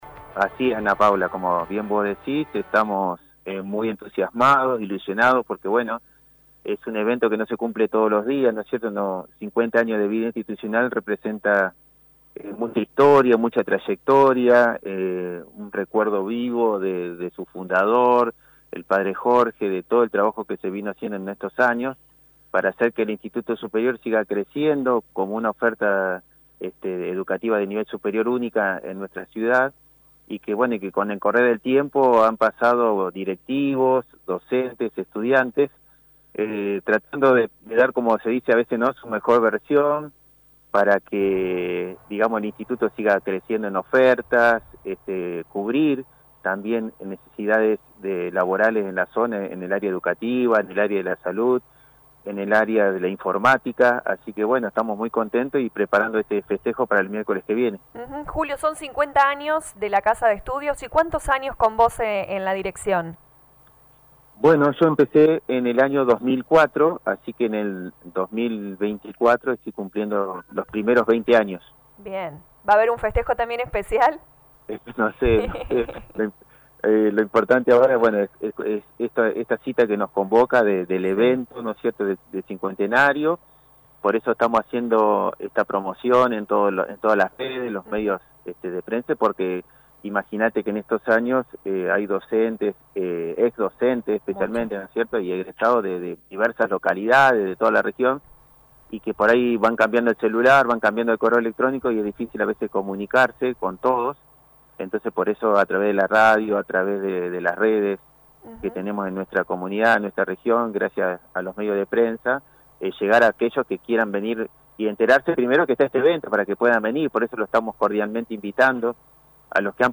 En diálogo con LA RADIO 102.9 FM